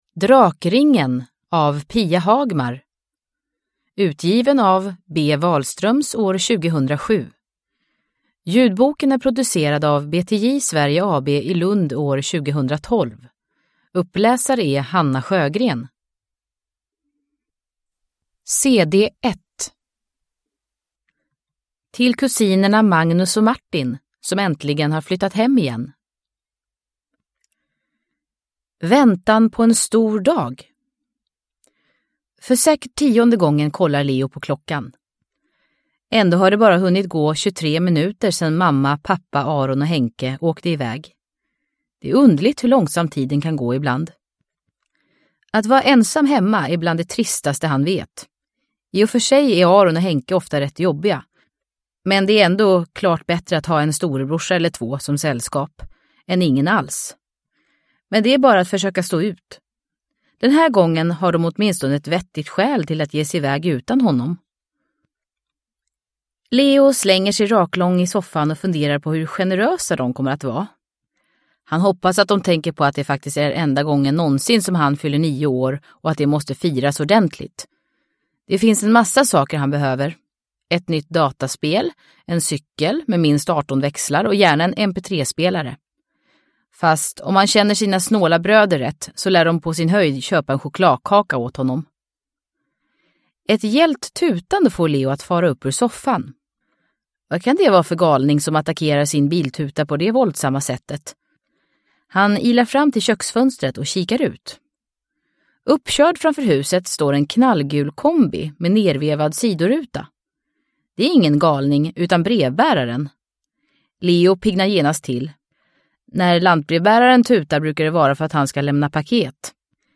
Drakringen – Ljudbok – Laddas ner